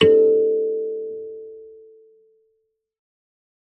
kalimba2_wood-A4-mf.wav